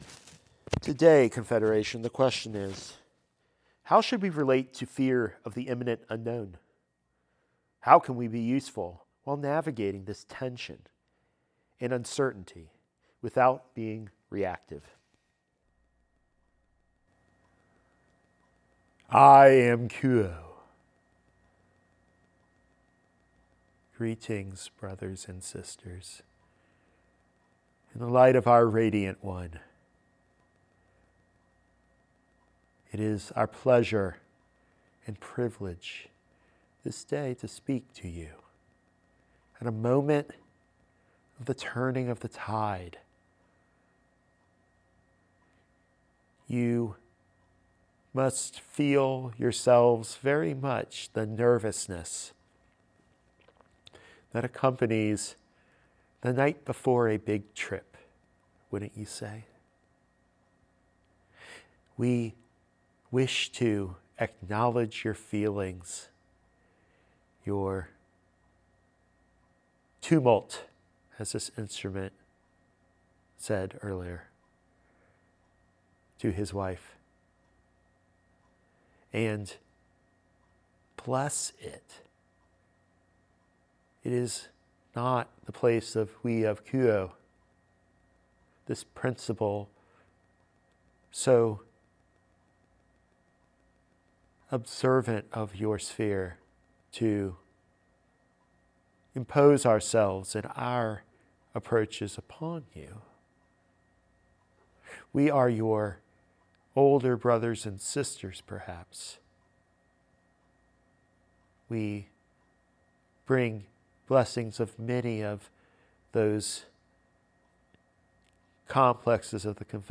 Q’uo speaks to the Richmond circle at a moment of heightened tension and fear on the planet and in the members’ lives.